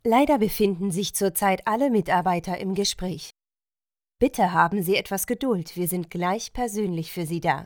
sehr variabel, hell, fein, zart, markant
Audio Drama (Hörspiel), Audiobook (Hörbuch), Children's Voice (Kinderstimme), Game, Lip-Sync (Synchron), Narrative, Scene, Trick